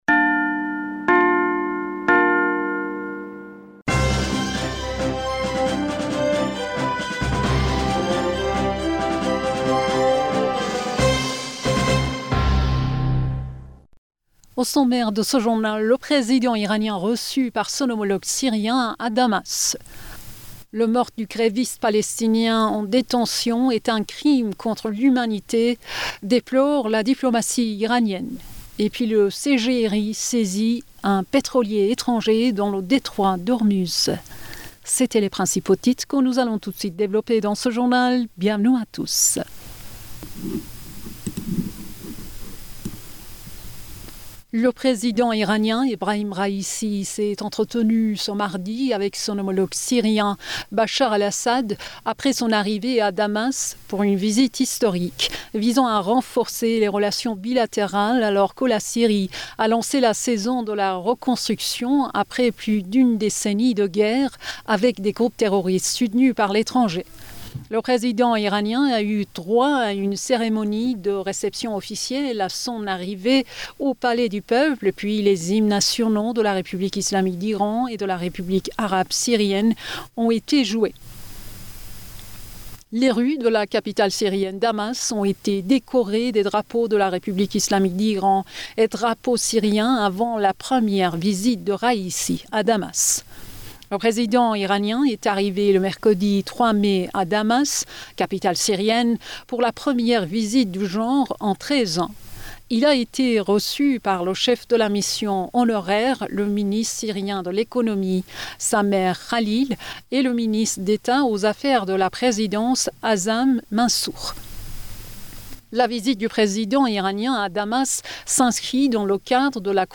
Bulletin d'information du 03 Mai 2023